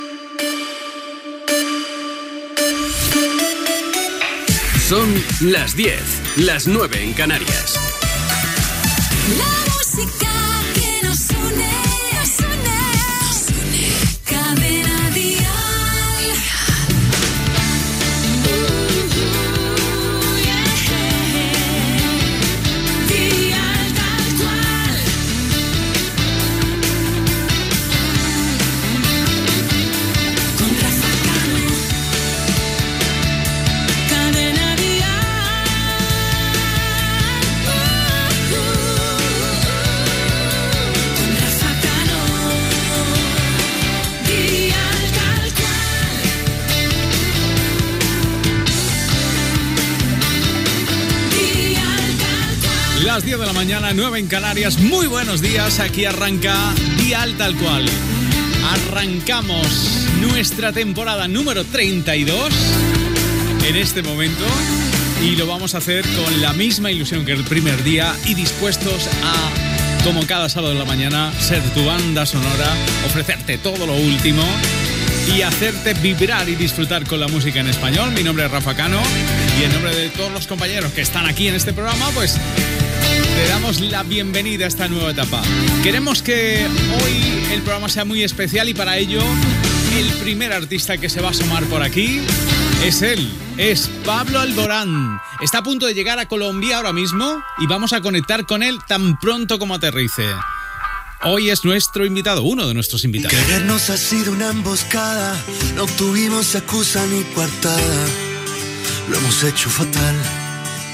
Indicatiu de la ràdio, careta, hora i inici de la temporada número 32 del programa
Musical
FM